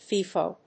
ファイフォ